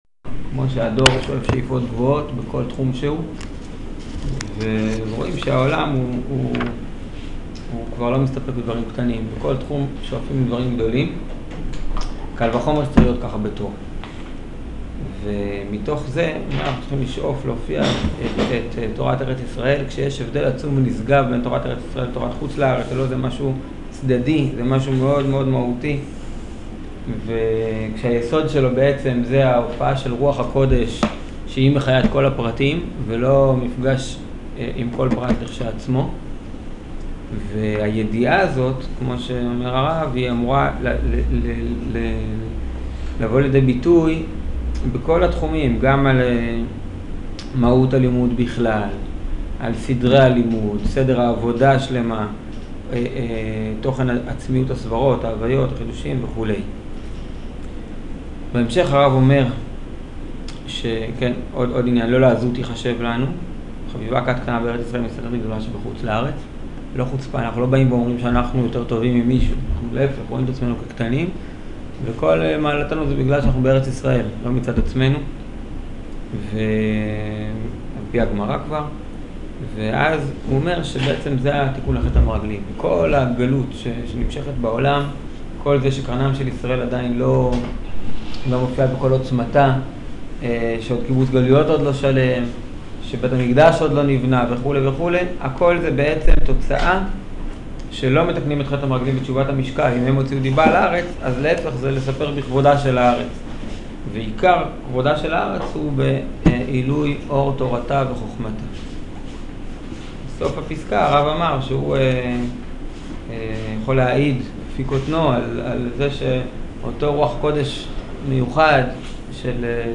שיעור אגרת צ"ו